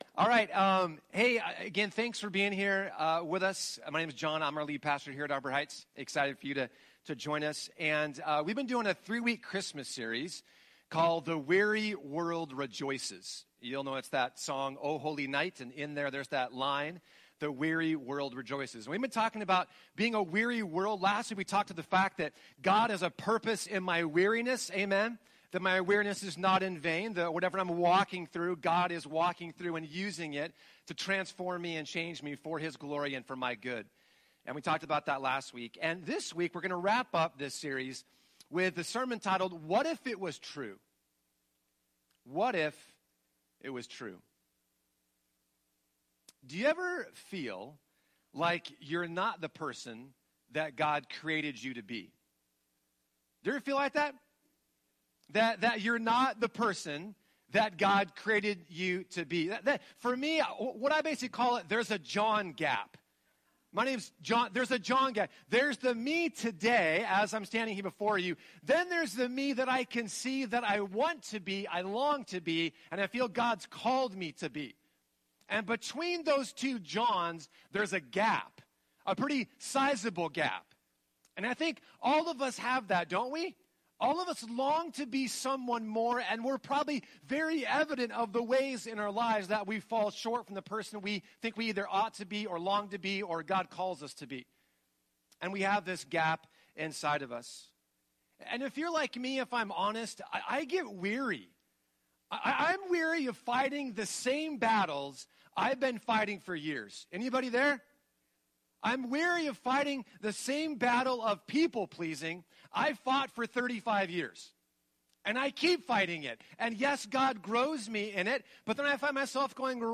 Sermons | Arbor Heights Community Church